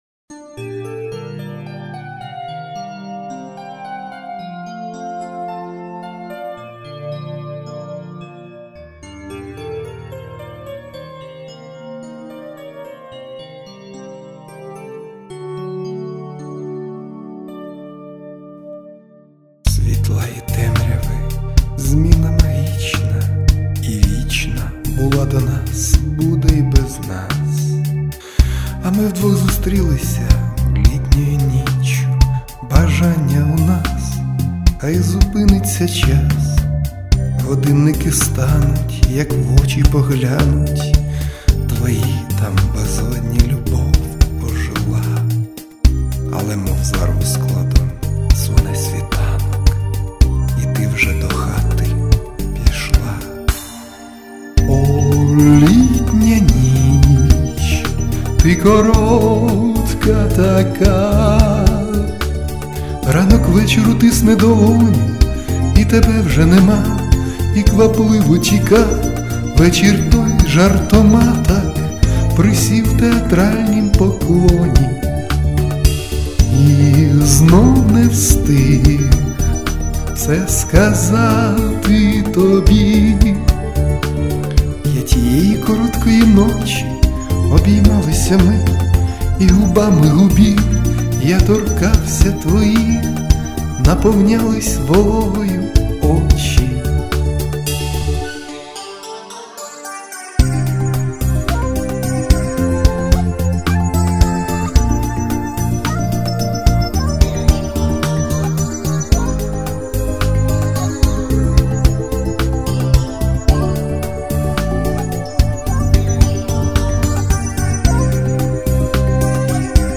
сл., муз., аранж., шепіт
16 гармония в классном каскаде у Вас! слушается так,что аж заслушиваюсь... hi give_rose